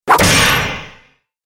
دانلود آهنگ دعوا 48 از افکت صوتی انسان و موجودات زنده
جلوه های صوتی
دانلود صدای دعوا 48 از ساعد نیوز با لینک مستقیم و کیفیت بالا